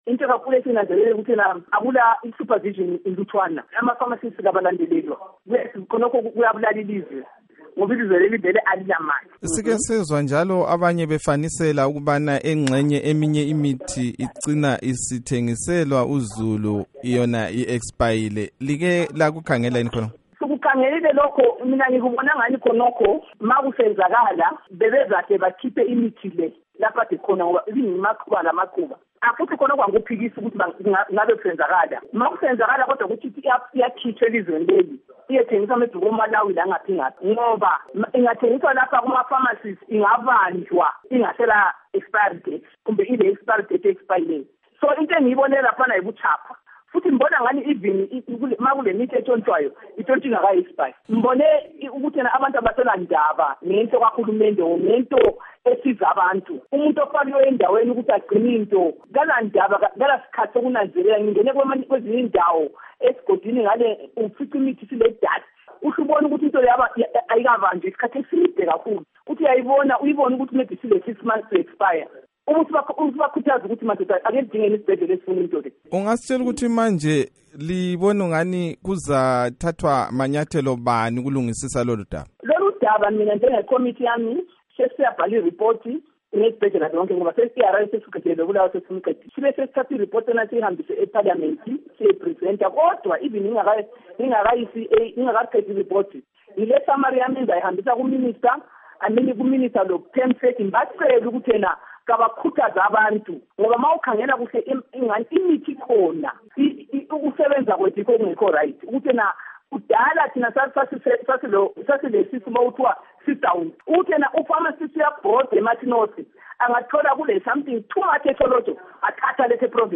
Ingxoxo loNkosikazi Ruth Labode